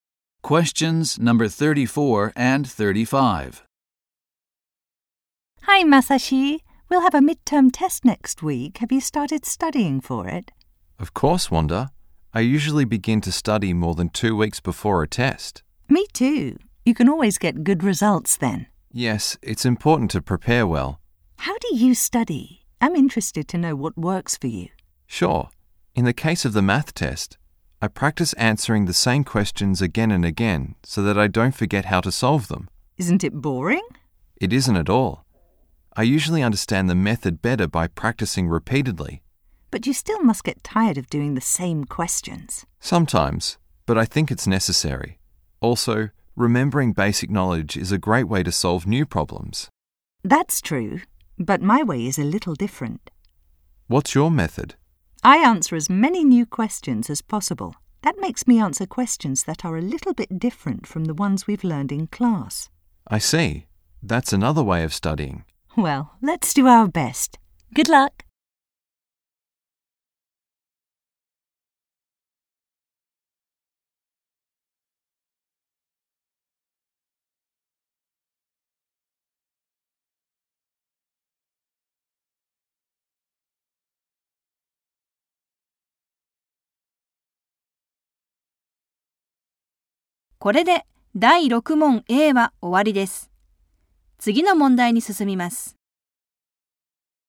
第4回 第6問A 問34・35 Faster Version 1:37 2.23MB
4_6a_Faster.mp3